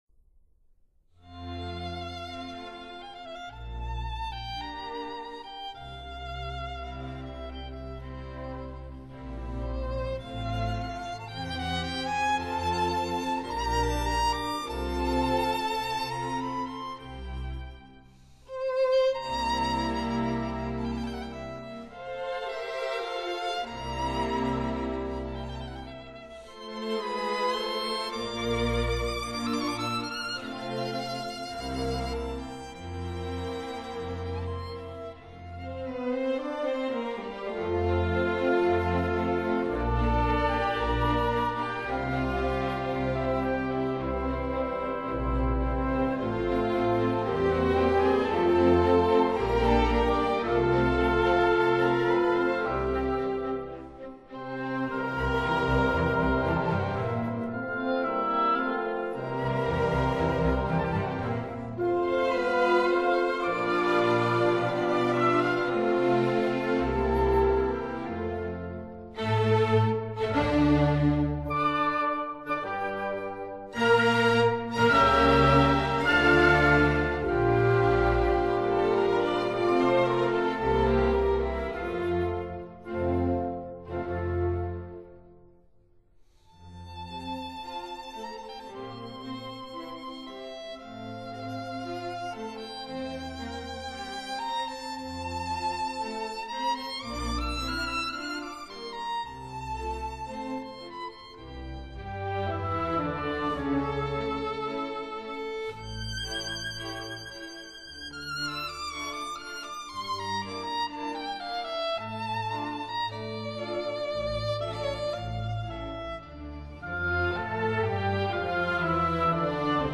可當小提琴名曲古典入門聆賞強碟;